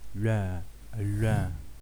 File:Labialized alveolar lateral approximant.ogg
English: Labialized alveolar lateral approximant